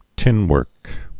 (tĭnwûrk)